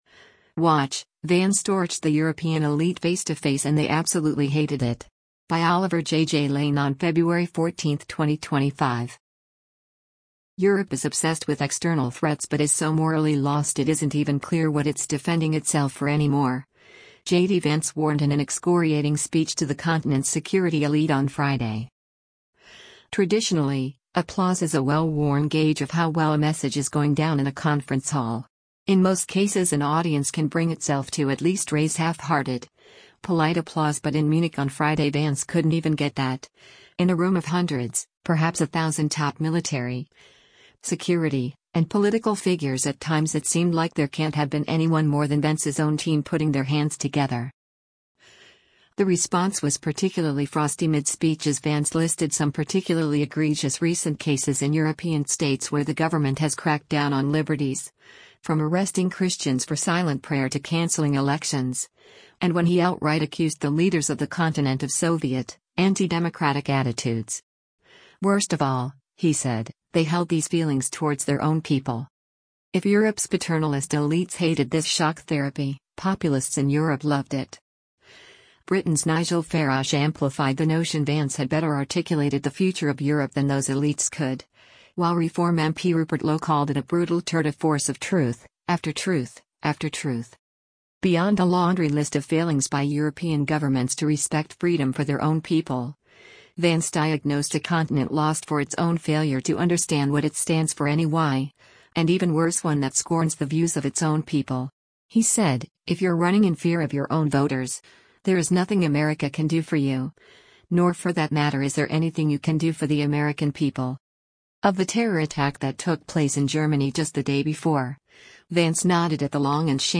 Europe is obsessed with external threats but is so morally lost it isn’t even clear what it’s defending itself for any more, JD Vance warned in an excoriating speech to the continent’s security elite on Friday.
In most cases an audience can bring itself to at least raise half-hearted, polite applause but in Munich on Friday Vance couldn’t even get that: in a room of hundreds, perhaps a thousand top military, security, and political figures at times it seemed like there can’t have been anyone more than Vance’s own team putting their hands together.
The response was particularly frosty mid-speech as Vance listed some particularly egregious recent cases in European states where the government has cracked down on liberties, from arresting Christians for silent prayer to cancelling elections, and when he outright accused the leaders of the continent of Soviet, anti-democratic attitudes.